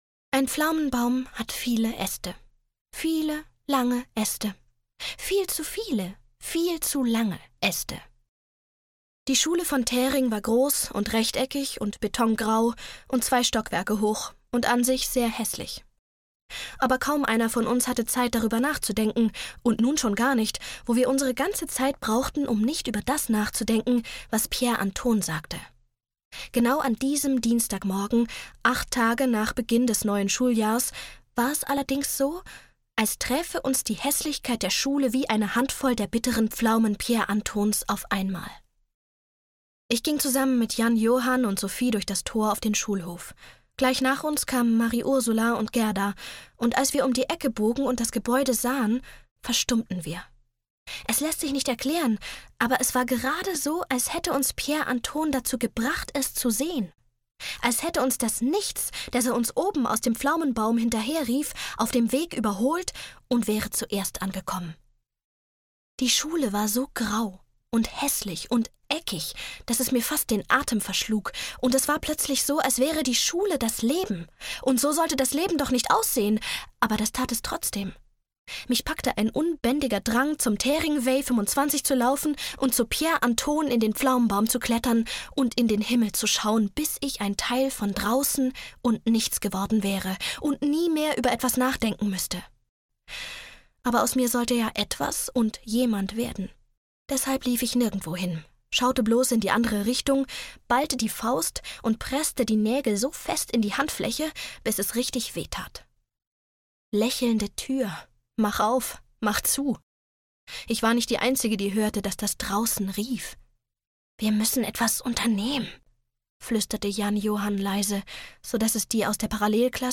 Nichts. Was im Leben wichtig ist - Janne Teller - Hörbuch